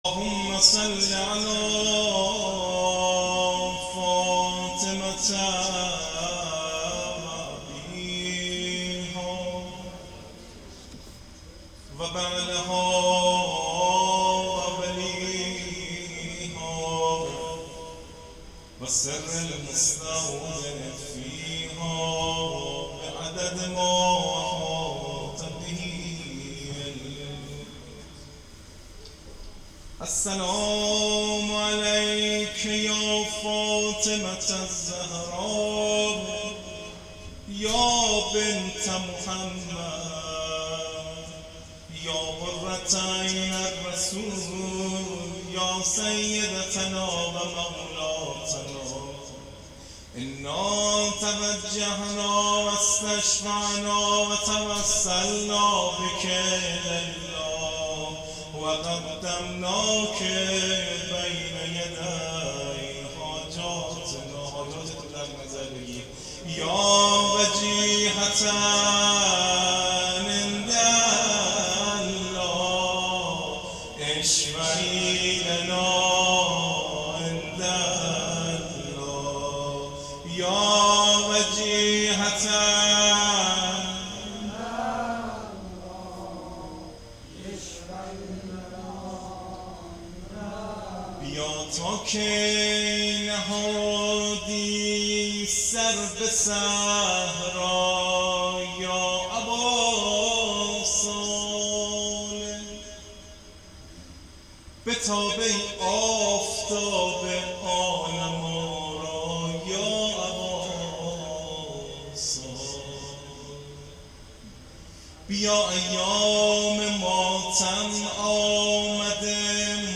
روضه شهادت حضرت زهرا س